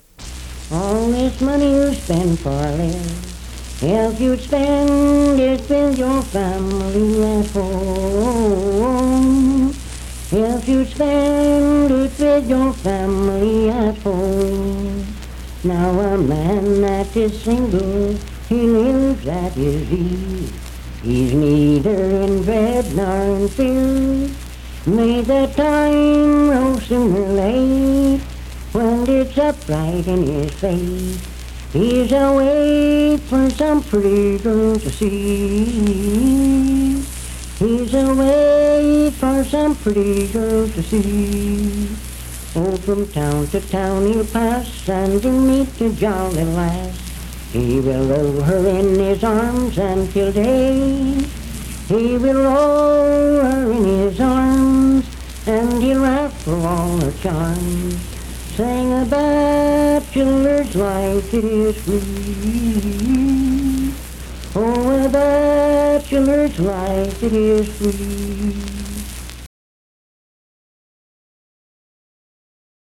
Unaccompanied vocal music
Verse-refrain 6(4-5w/R). Performed in Sandyville, Jackson County, WV.
Voice (sung)